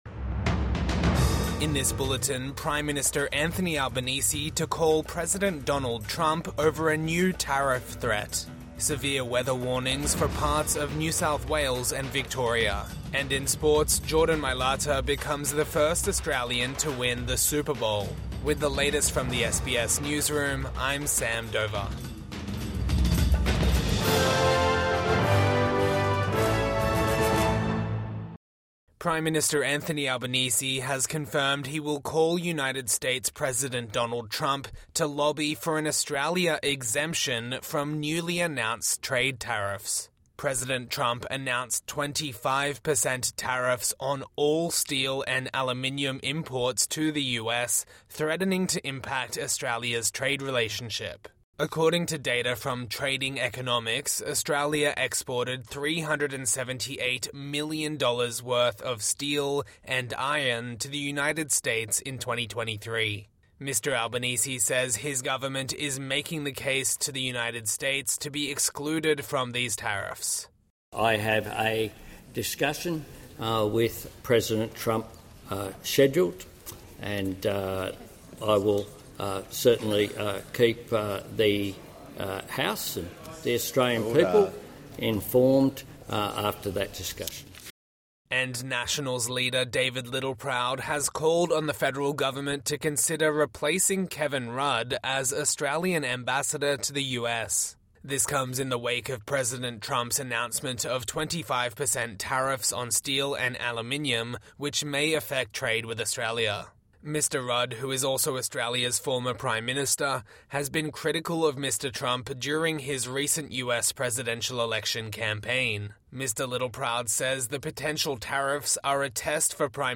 Evening News Bulletin 10 February 2025